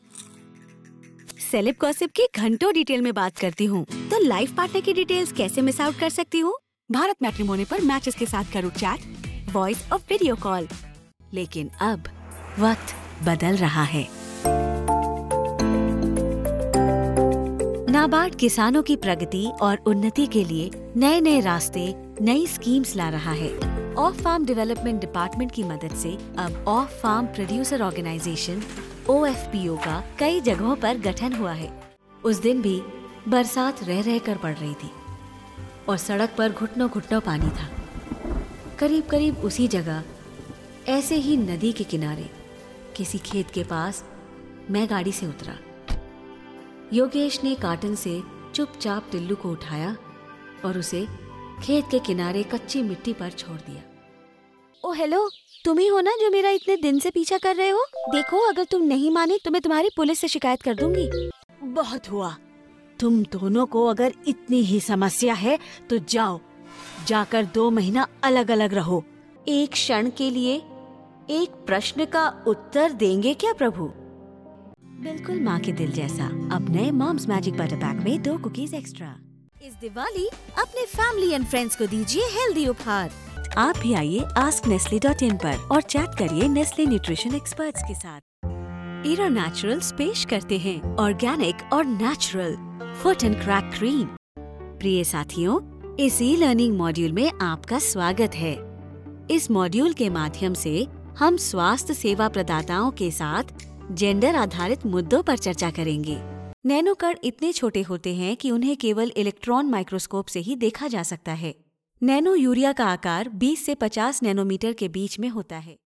A Storyteller | Voice Artist | WARM, HONEST and BELIEVABLE voice for your brand | let's discuss your story | Broadcast quality professional studio setup | Equipped with SOURCE CONNECT| Recording for any studio globally
Hindi Voice Range